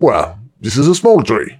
treeappears01.ogg